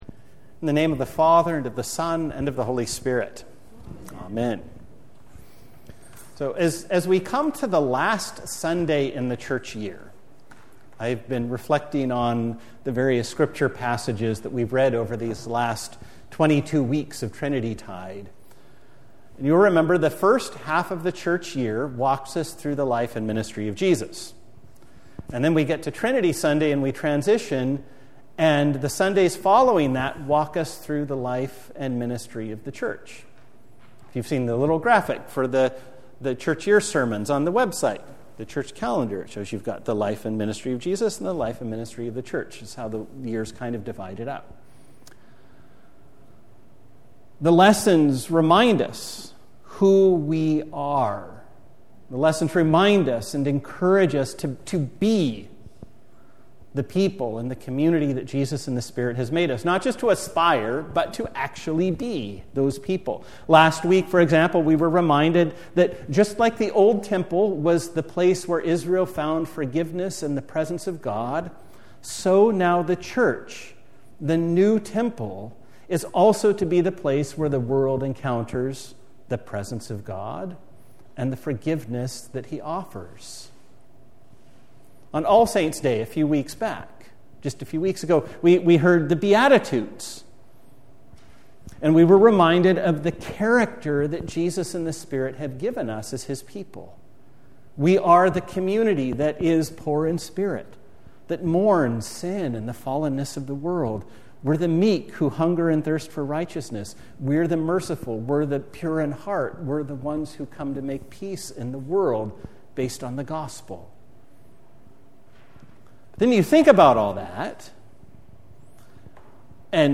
A Sermon for the Sunday Next Before Advent